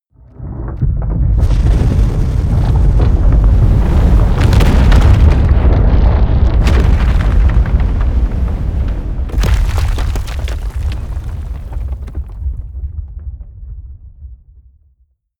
Gemafreie Sounds: Erdbeben